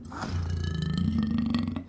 spawners_mobs_uruk_hai_neutral.3.ogg